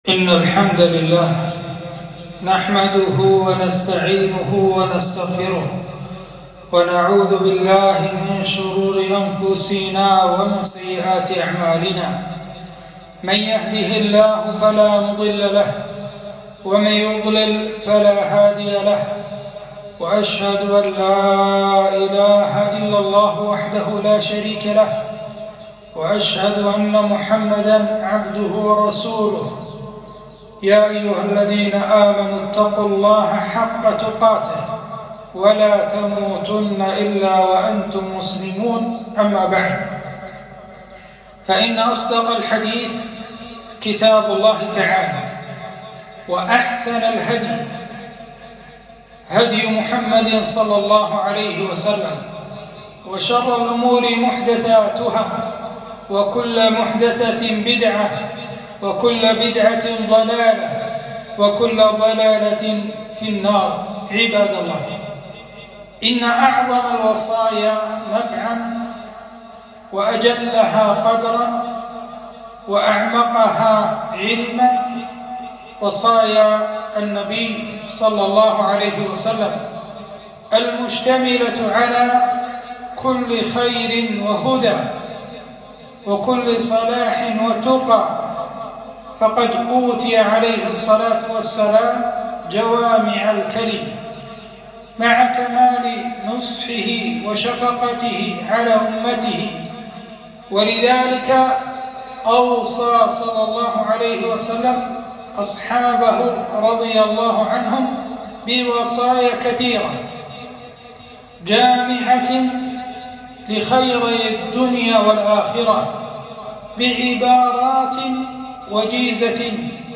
خطبة الجمعة في مسجد دبيس الرشيدي
خطبة - علاج الغضب